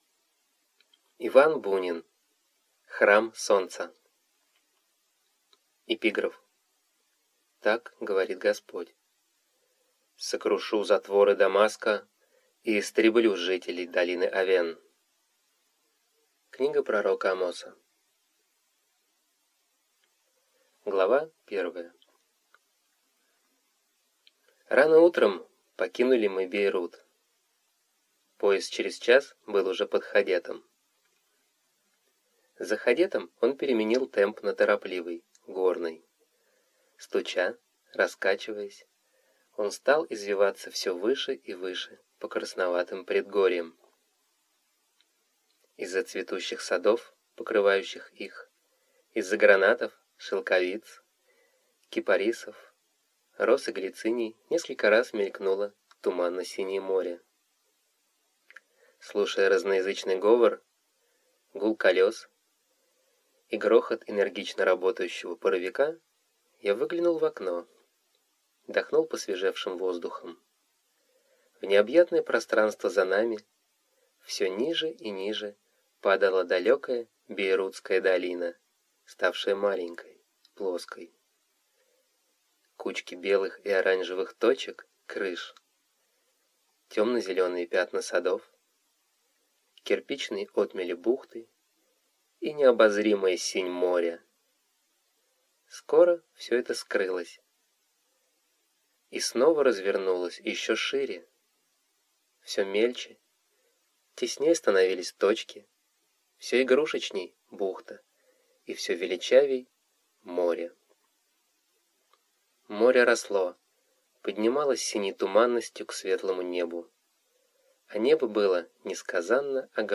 Аудиокнига Храм Солнца | Библиотека аудиокниг